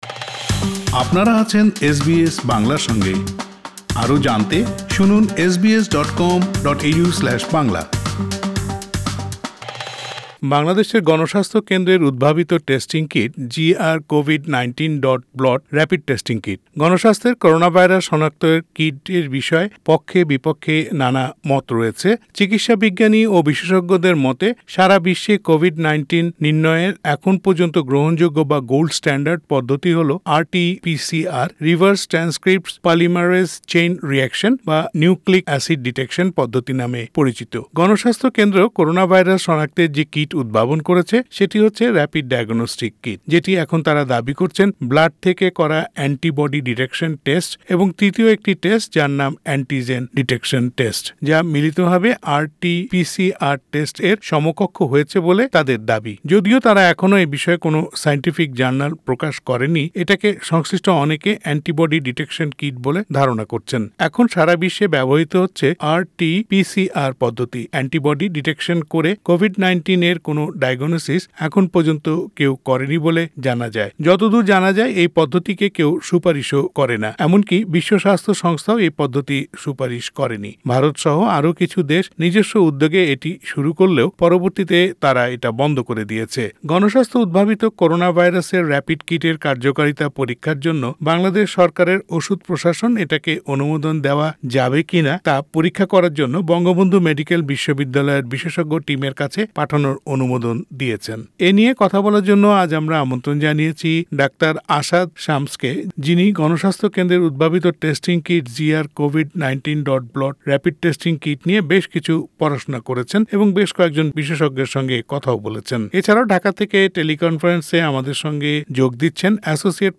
ঢাকা থেকে টেলি কনফারেন্সে যোগ দিয়েছেন